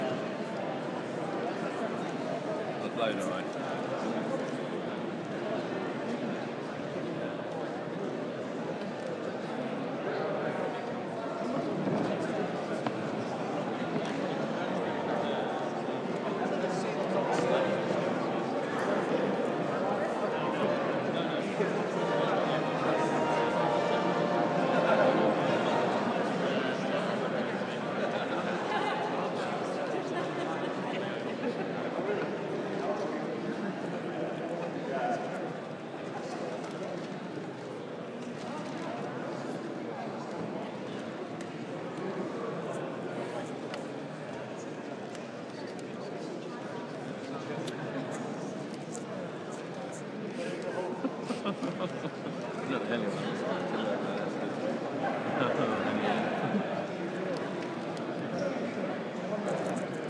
60 seconds of: Interesting North Cutler's Hall ambience